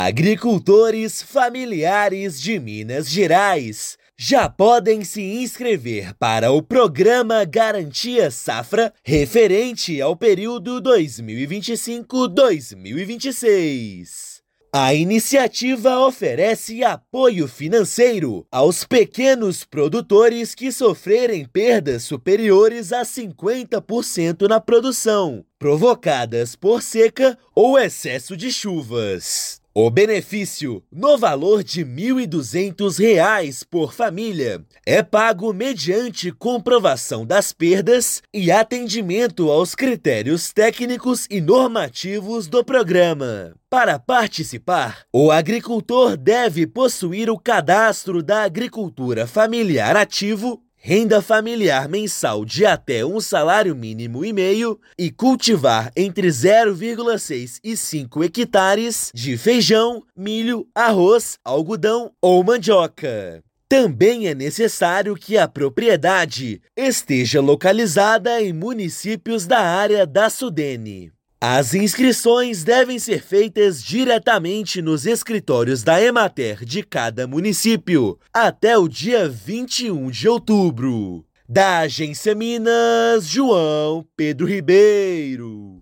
Prazo vai até o dia 21/10 para agricultores familiares da área da Sudene. Ouça matéria de rádio.